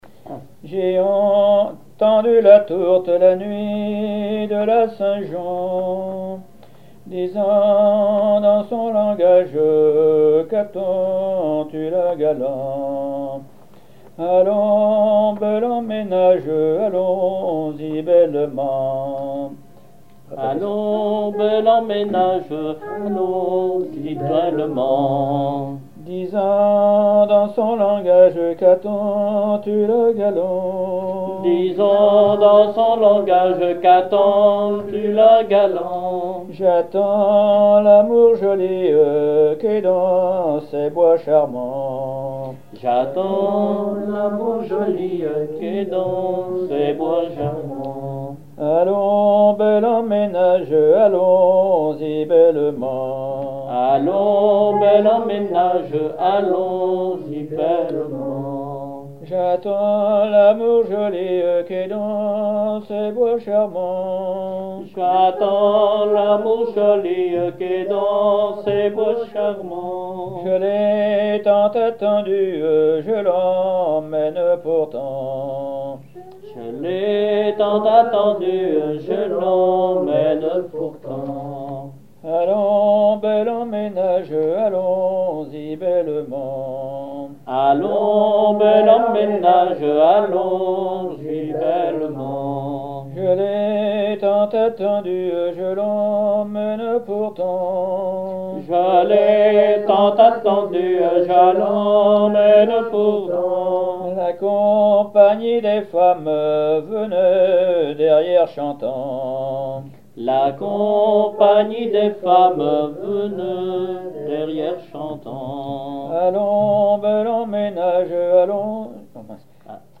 Mémoires et Patrimoines vivants - RaddO est une base de données d'archives iconographiques et sonores.
circonstance : fiançaille, noce
Genre laisse
Pièce musicale inédite